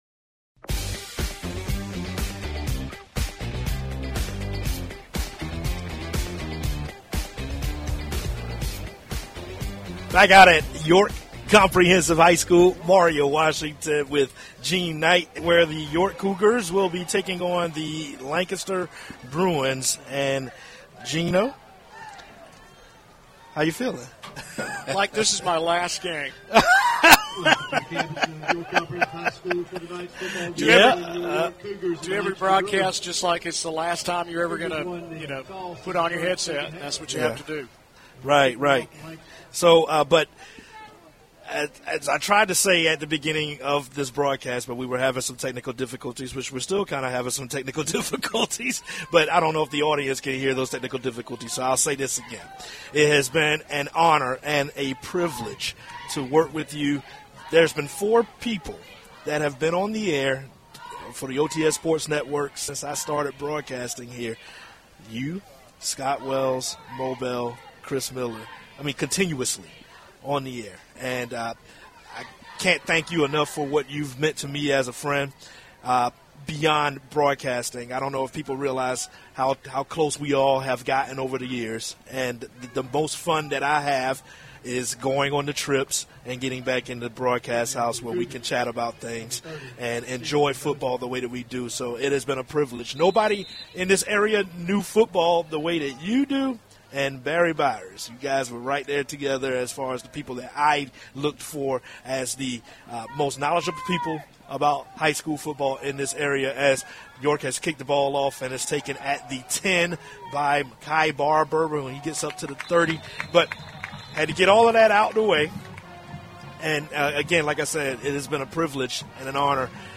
High School Sports